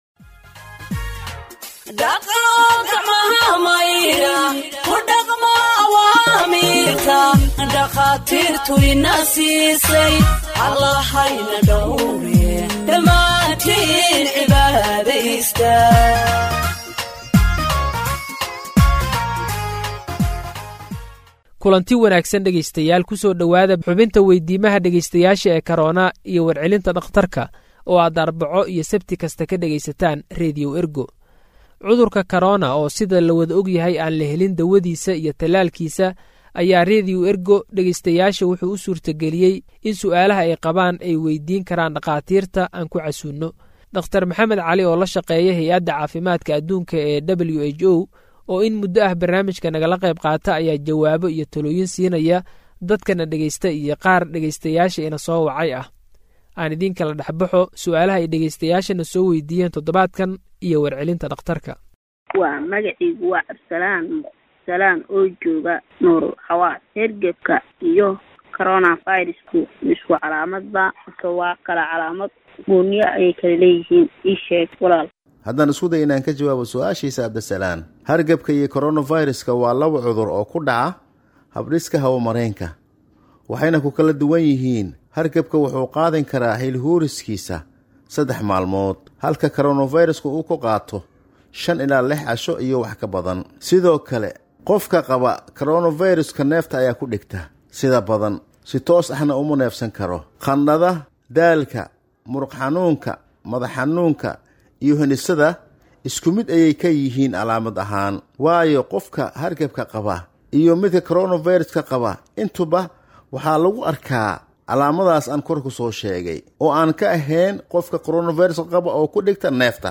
Health expert answers listeners’ questions on COVID 19 (37)
Radio Ergo provides Somali humanitarian news gathered from its correspondents across the country for radio broadcast and website publication.